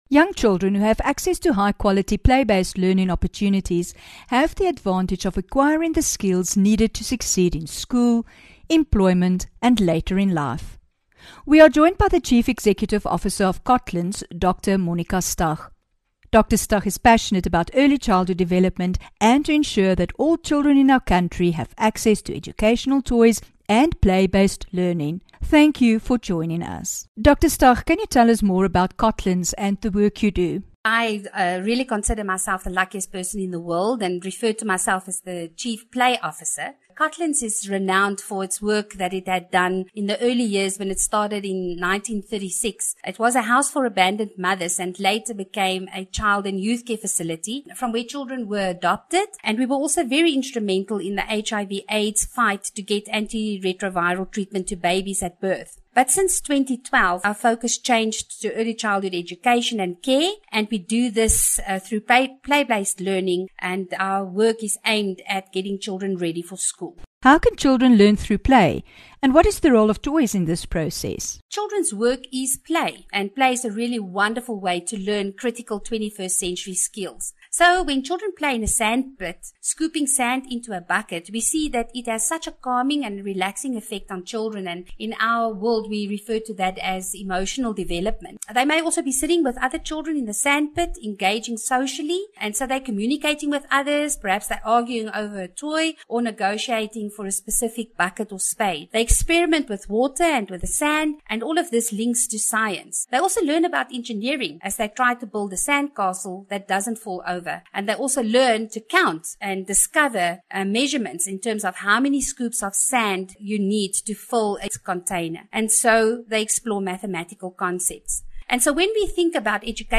12 Mar INTERVIEW